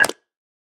Minecraft Version Minecraft Version 25w18a Latest Release | Latest Snapshot 25w18a / assets / minecraft / sounds / block / mud_bricks / step6.ogg Compare With Compare With Latest Release | Latest Snapshot